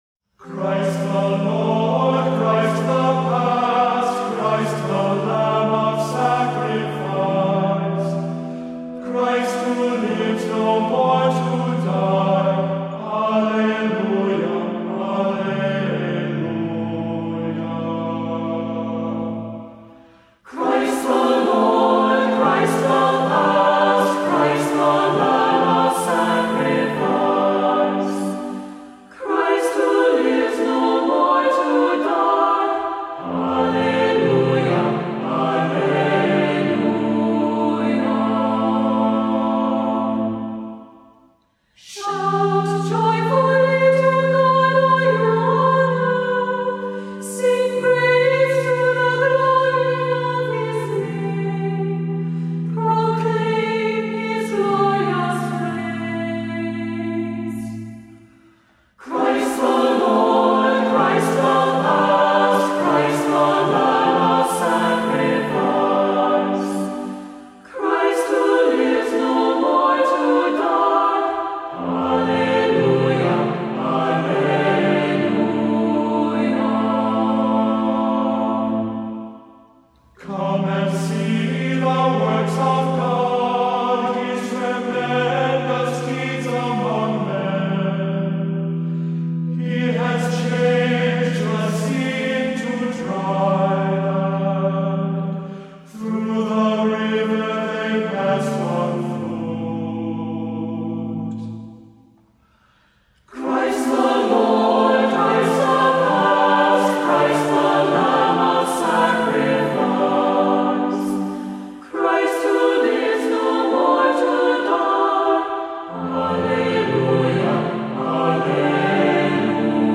Voicing: Assembly,SATB,Cantor